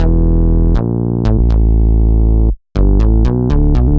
Index of /musicradar/french-house-chillout-samples/120bpm/Instruments
FHC_MunchBass_120-E.wav